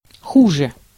Ääntäminen
Ääntäminen Tuntematon aksentti: IPA: /ˈʃlɛçtɐ/ Haettu sana löytyi näillä lähdekielillä: saksa Käännös Ääninäyte 1. хуже (huže) Schlechter on sanan schlecht komparatiivi.